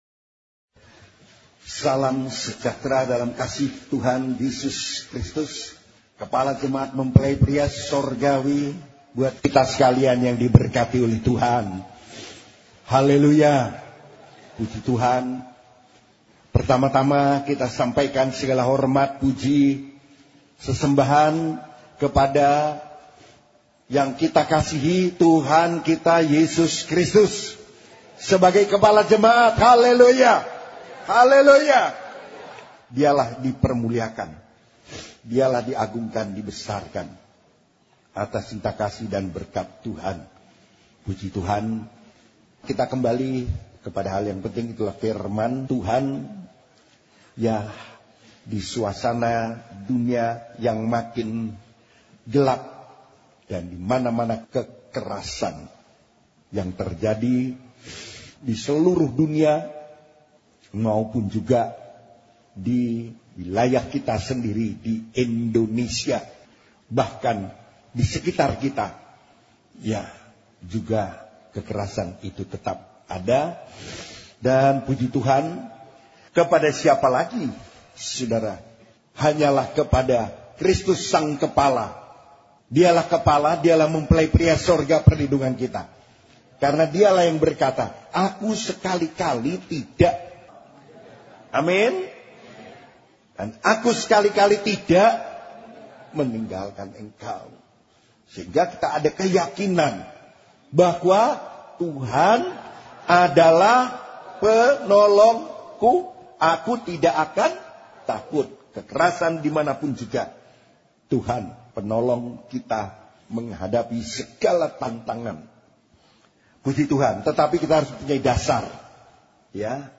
Khotbah (Audio)
Khotbah Pengajaran